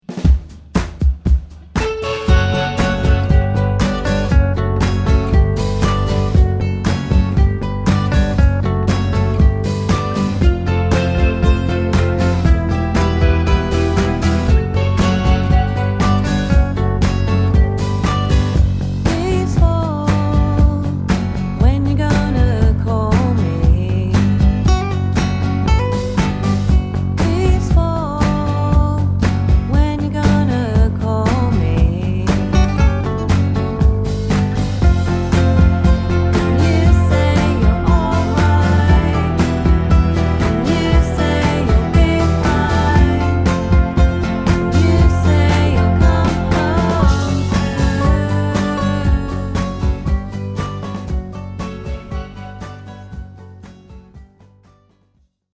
Rough demo tracks